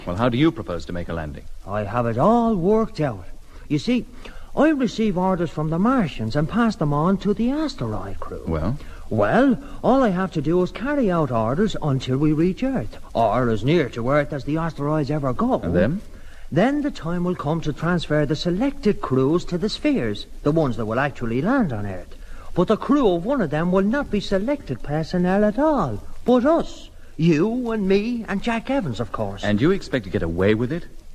Je schrijft “Jack Evans”, maar hoe spreek je dat uit?
Als je wil dat de Britten deze naam net zo uitspreken als de Nederlanders doen, moet je kennelijk Jack Evens schrijven.